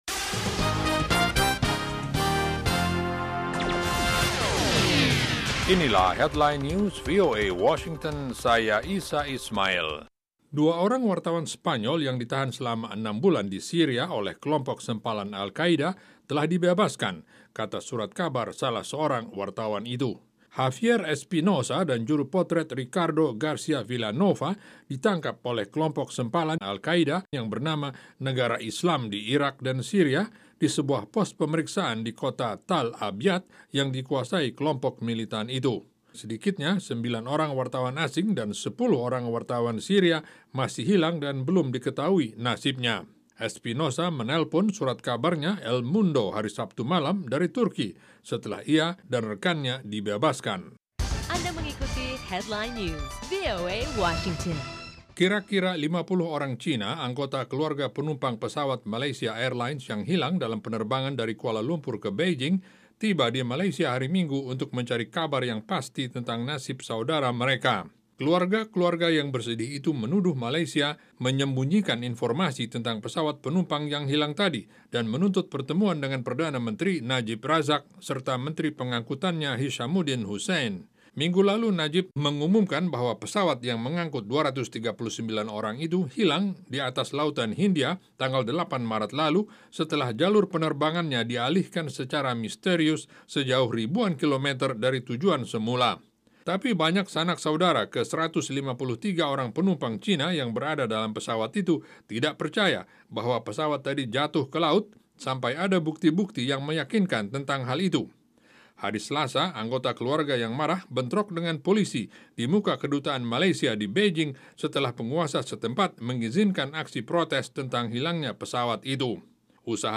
Simak berita terkini setiap 30 menit langsung dari Washington dalam Headline News, bersama para penyiar VOA yang setia menghadirkan perkembangan terakhir berita-berita internasional.